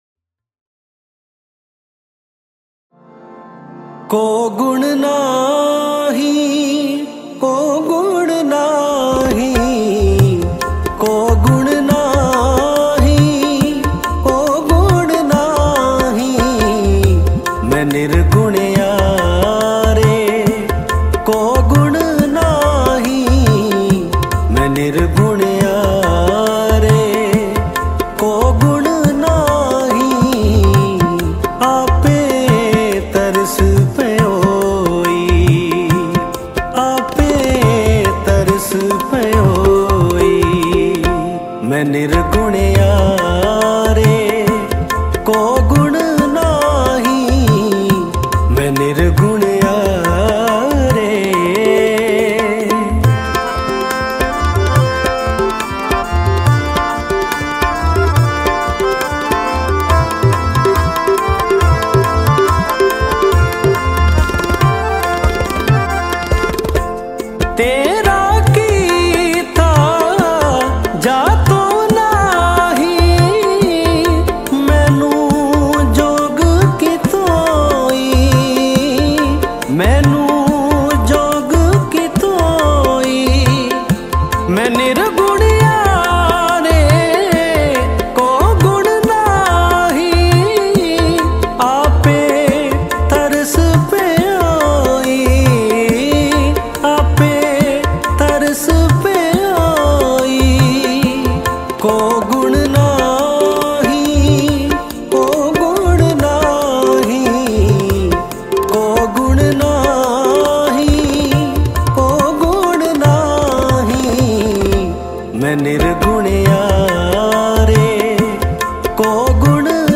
Mp3 Files / Gurbani Kirtan / 2025-Shabad Kirtan / Albums /